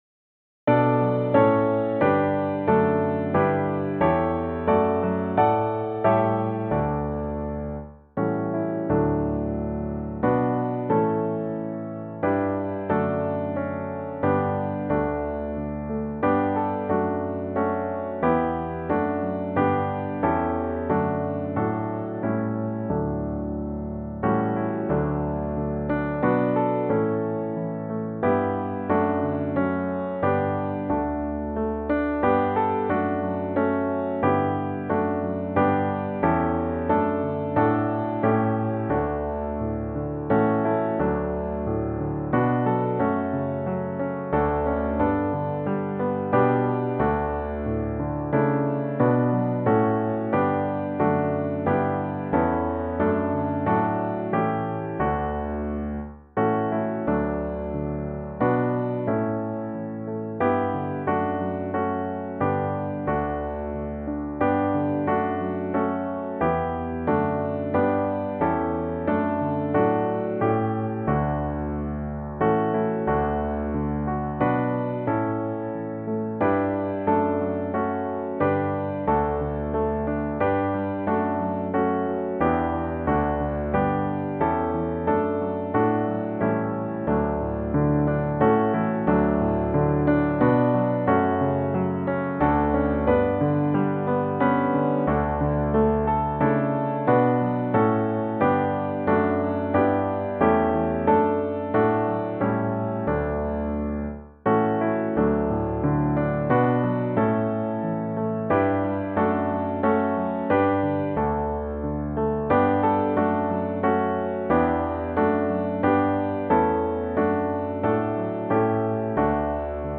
The-First-Noel-Key-of-D_1.wav